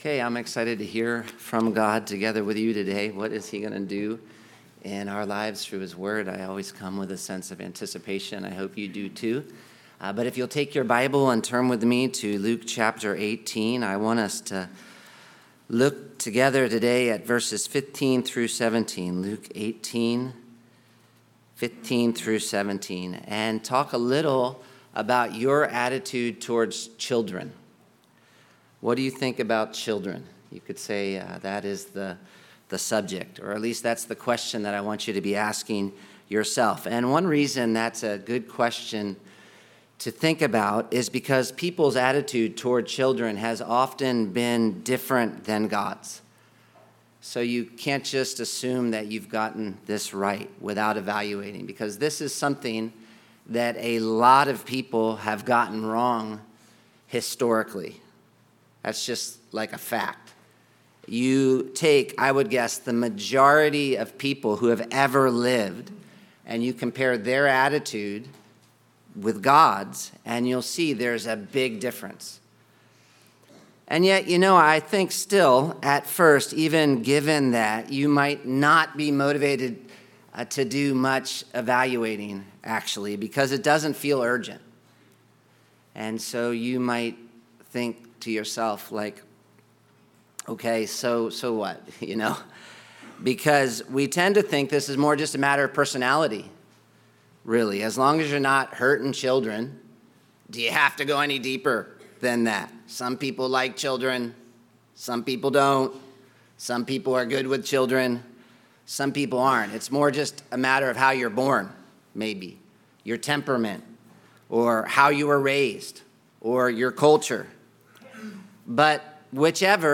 PASSAGE Luke 18:15-17 MINISTRY Sunday Worship SERIES Surprising Citizens: Who Really Belongs in God's Kingdom and Why It Matters TAGS Gospel of Luke RELATED RESOURCES Why are the Smallest the Greatest.pdf